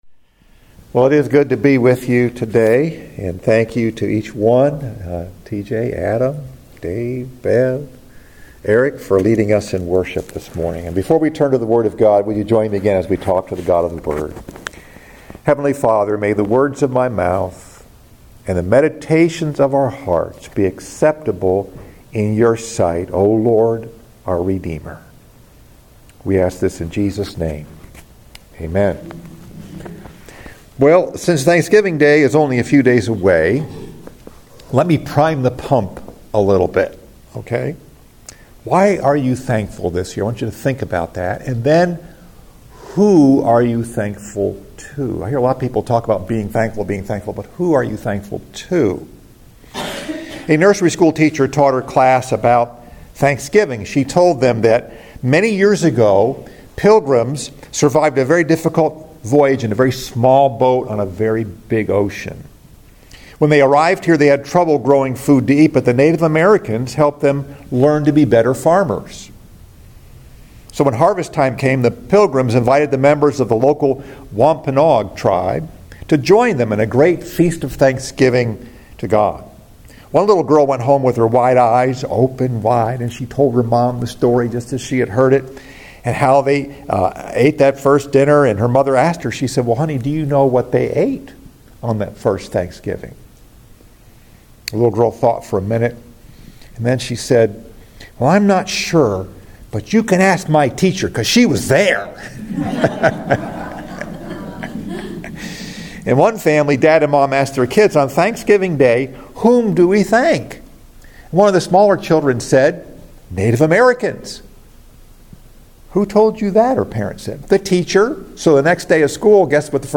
Message: “Thank God” Scripture: Psalm 92:1